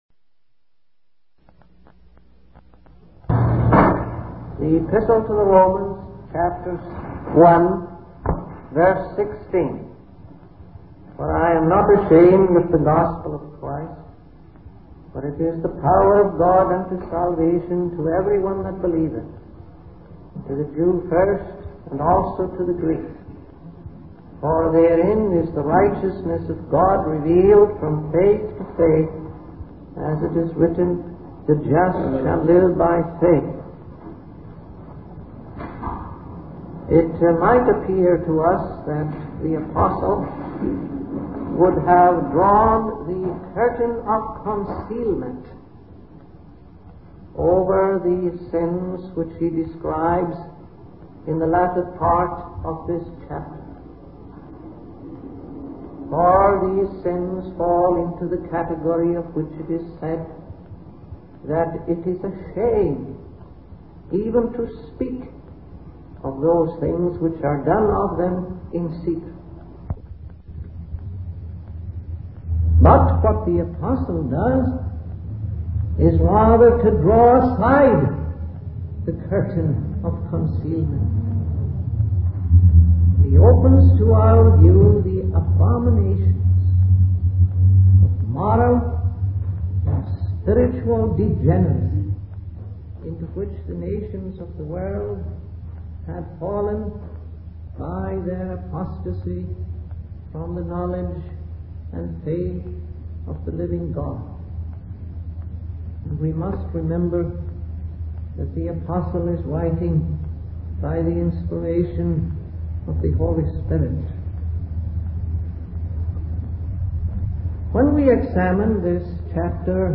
In this sermon, the preacher emphasizes the power and authority of the word of God.